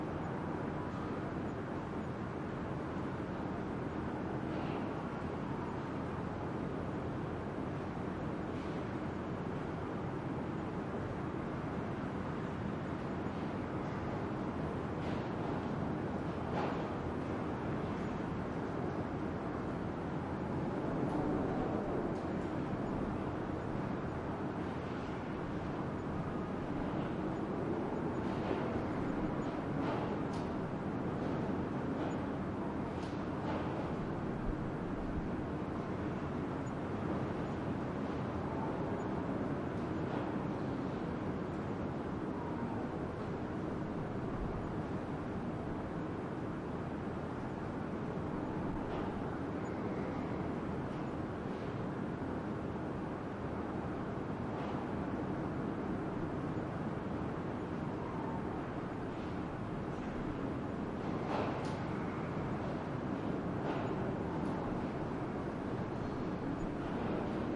加沙 " 酒店楼梯外的大风和远处工作人员准备东西的声音隔夜2 加沙 2016年
Tag: 楼梯 酒店 外面